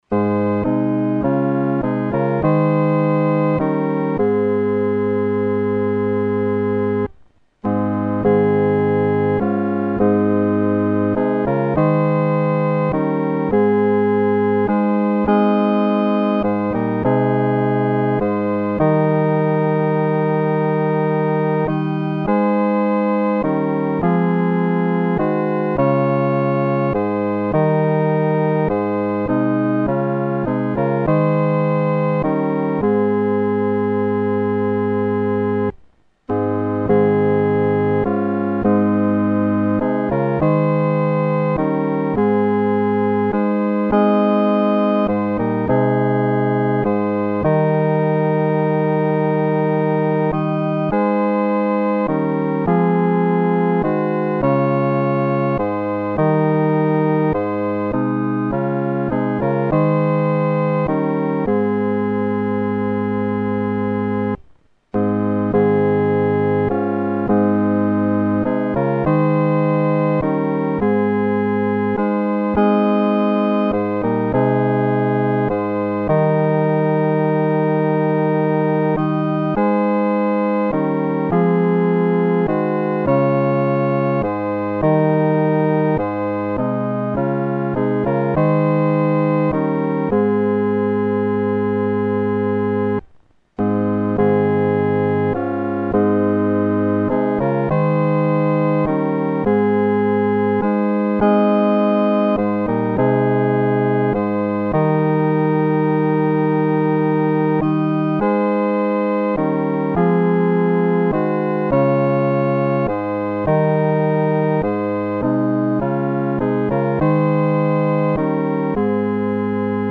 四声伴奏